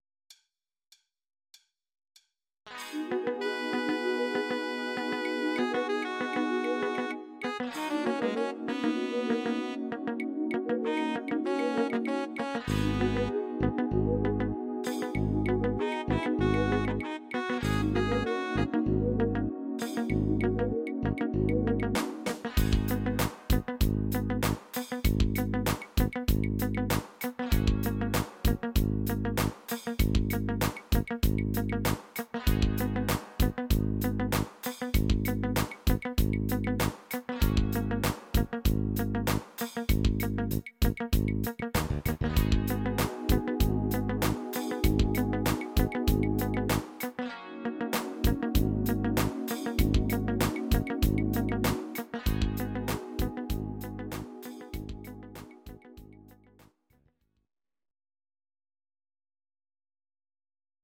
Audio Recordings based on Midi-files
Pop, Musical/Film/TV, Duets, 1990s